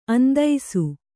♪ andaisu